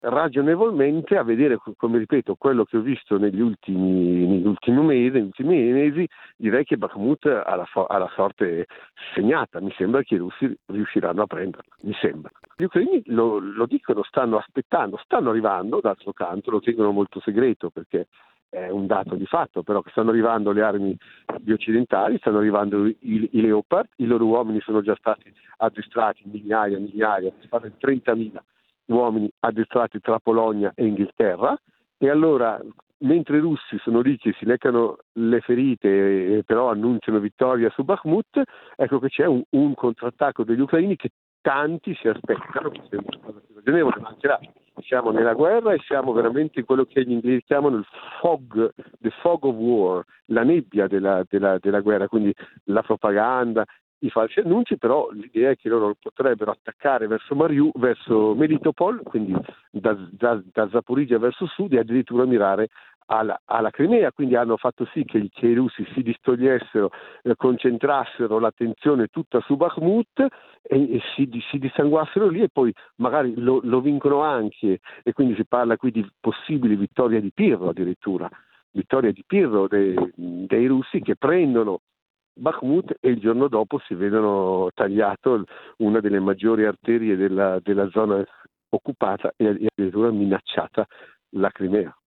Ecco ai nostri microfoni la sua lettura di quello che sta accadendo